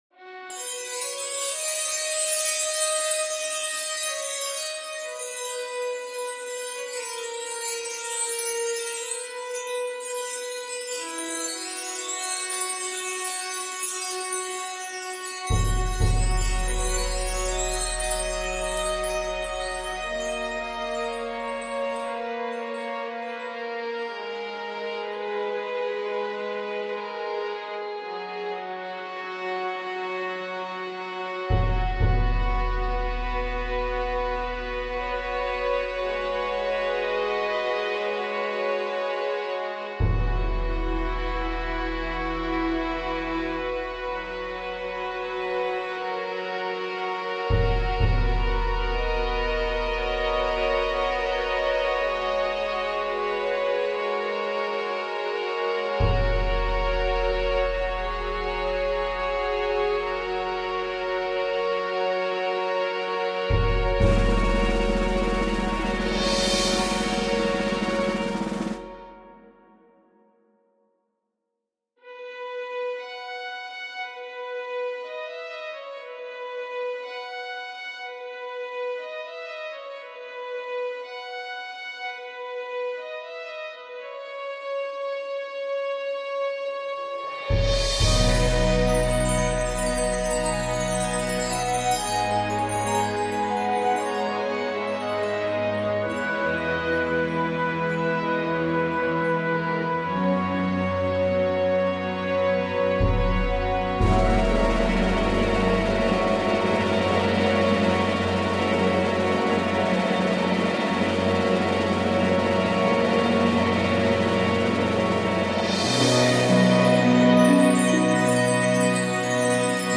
This peice saw me work with six of the most gorgeous presets I have ever heard in my two years of producing.